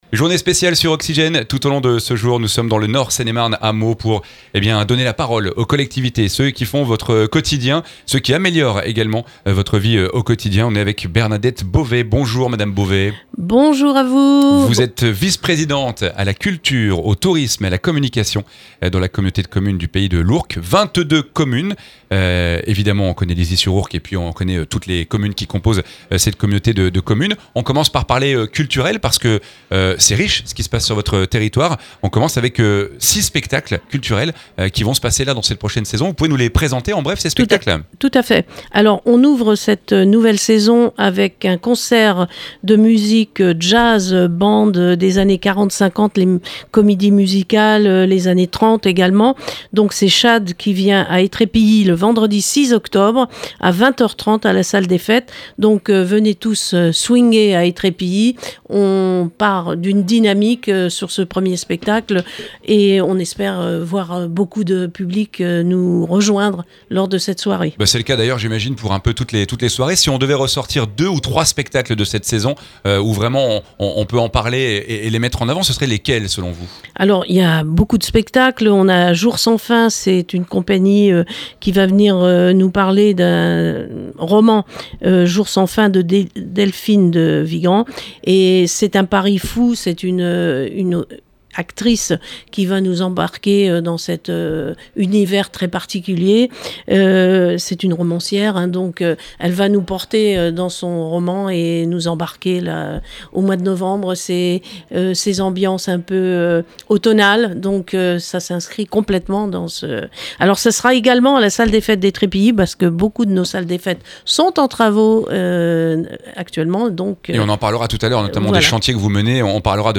Les collectivités et acteurs locaux du département se sont donnés rendez-vous dans les locaux de Meaux pour une journée spéciale sur Oxygène. L’occasion pour Bernadette Beauvais d’évoquer les sujets majeurs de la communauté de commune du Pays de l'Ourcq.